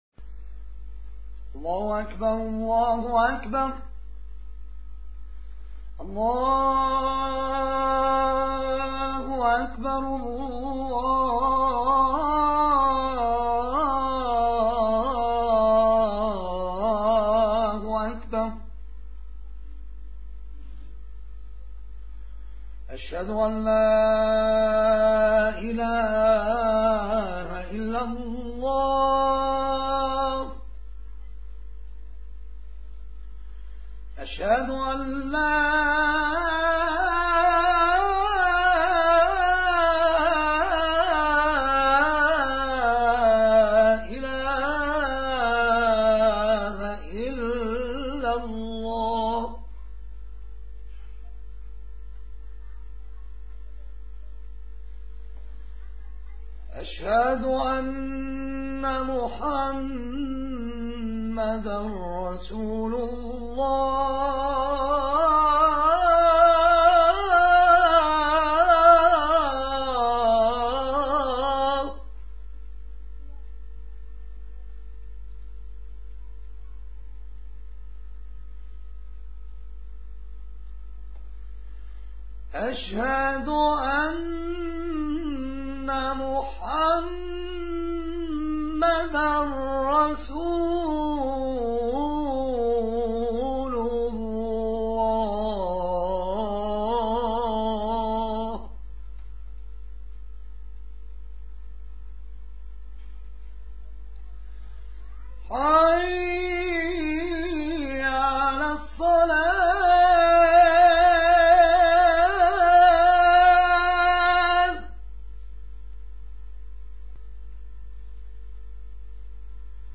اذان در مقام سه گاه
segah-Azan-Segah.mp3